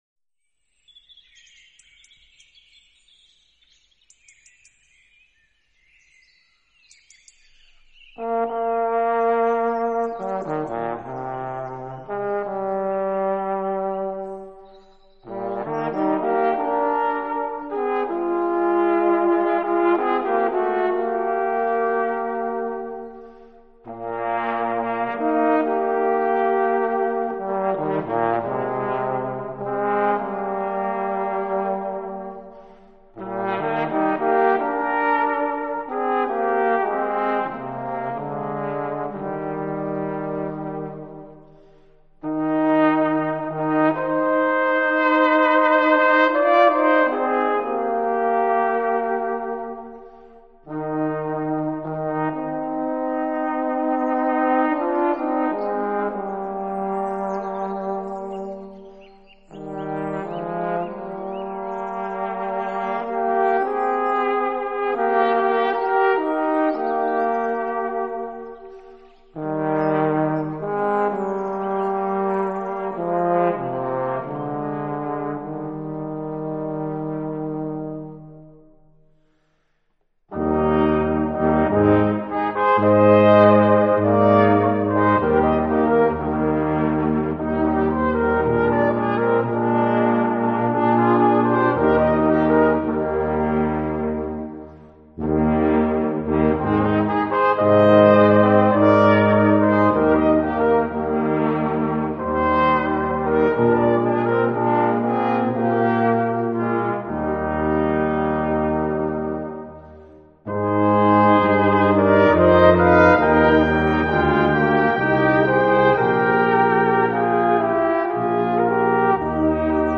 Besetzung: Ensemblemusik für 4 Blechbläser
1./2. Flügelhorn in B
Posaune in C/B
Tuba in C/Es/B